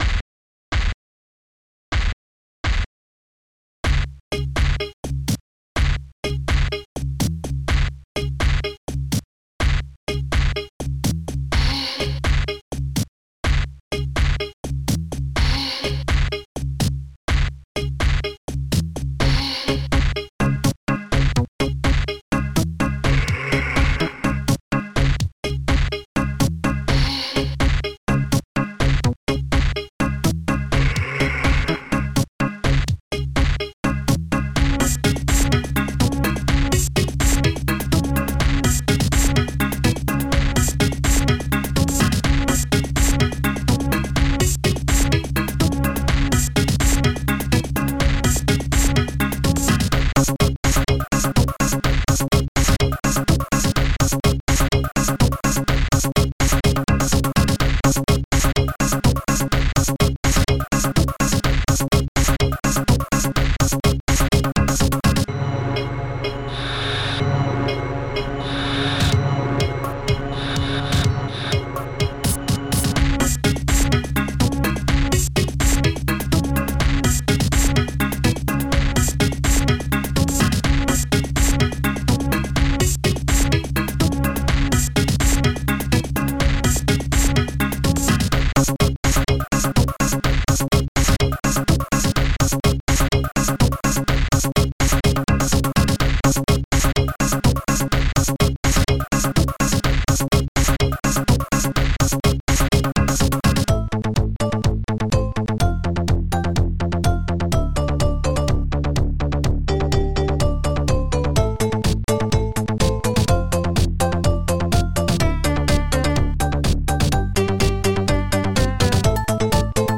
megabassdrum
megahihat2